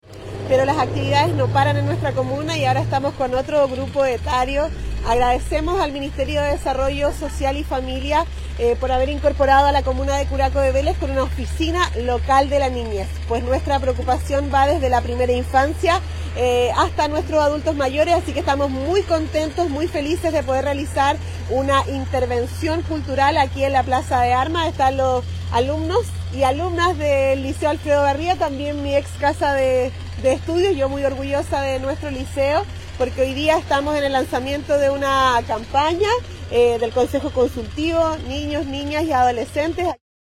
Al respecto, la jefa comunal, señaló: